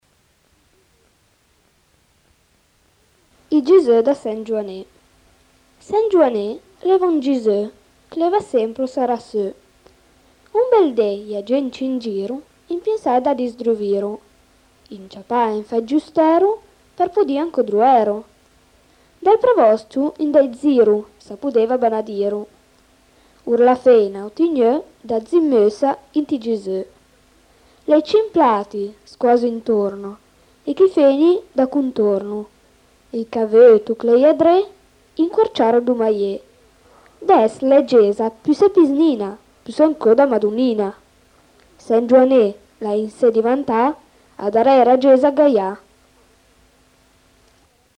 poesia di Angelo Jorio, recitata